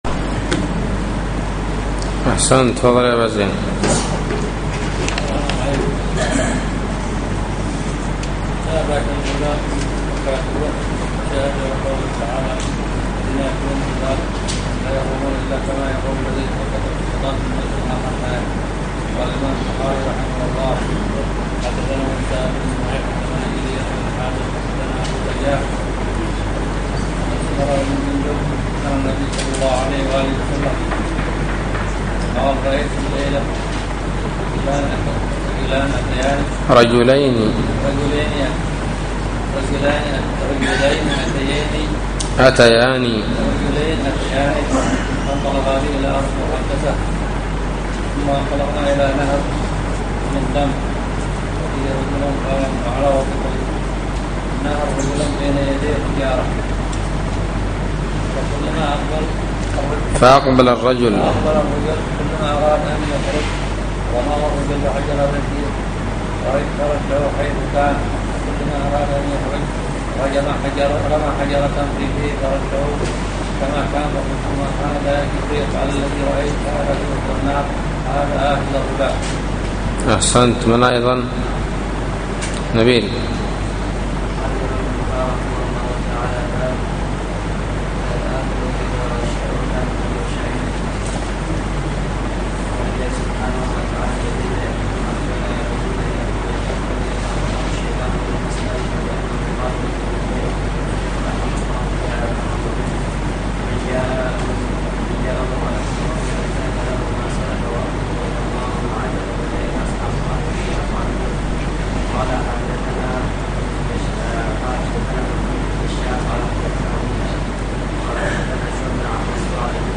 الدرس الثاني والعشرون : بَاب: مُوكِلِ الرِّبَا